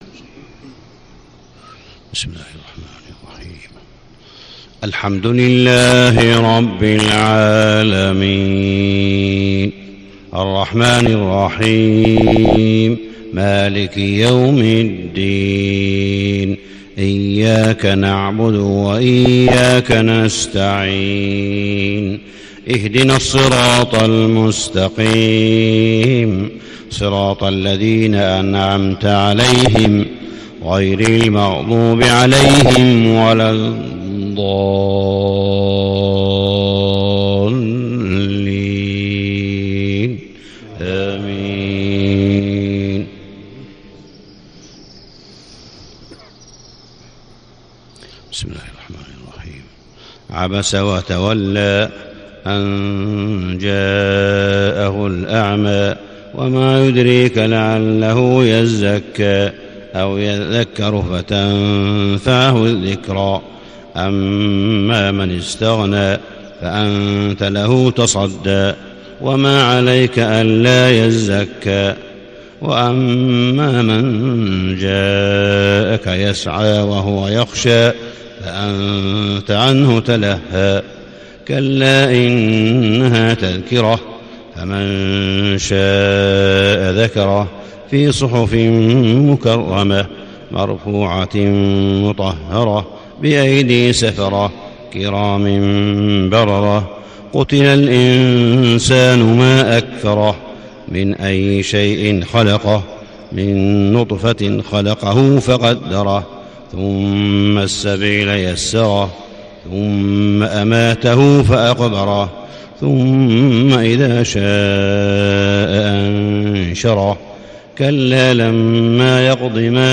صلاة الفجر 24 شعبان 1437هـ سورتي عبس و البروج > 1437 🕋 > الفروض - تلاوات الحرمين